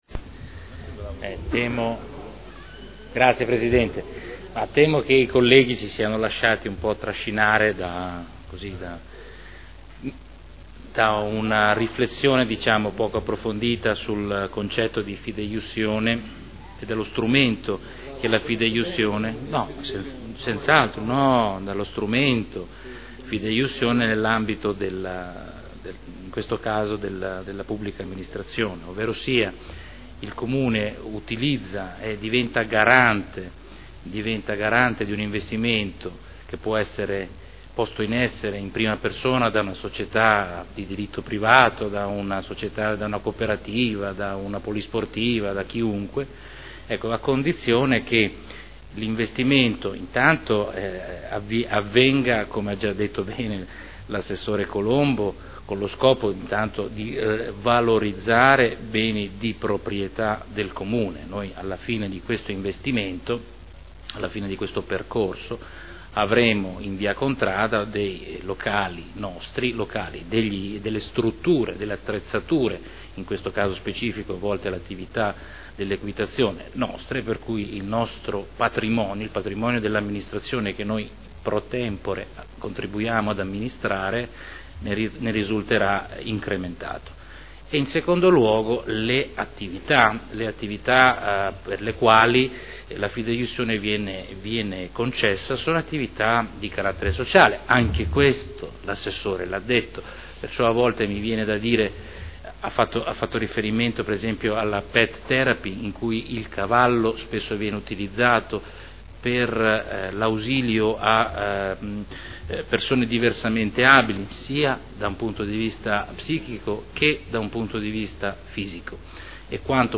Seduta del 30/05/2011. Dichiarazione di voto su proposta di deliberazione: Concessione di garanzia fidejussoria a favore della Banca Popolare dell’Emilia Romagna per i mutui in corso di stipulazione con Equipenta S.r.l.